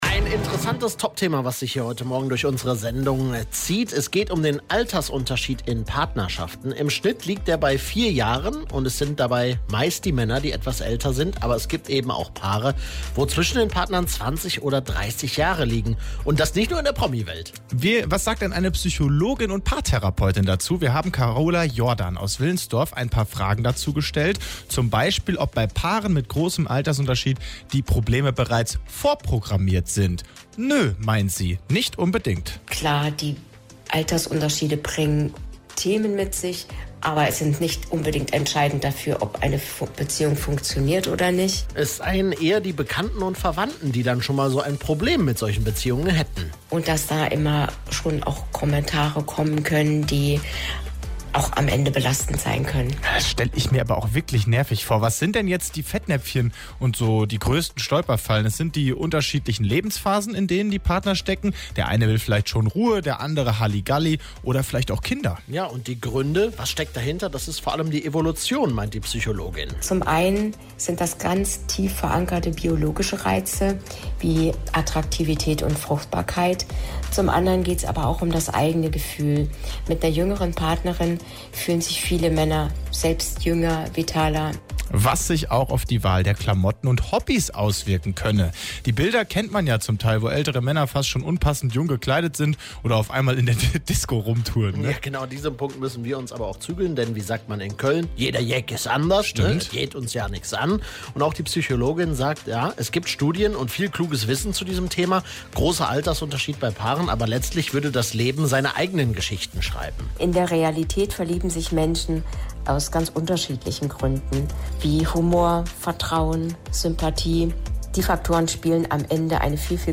mitschnitt-altersunterschied-bei-paaren-v1.mp3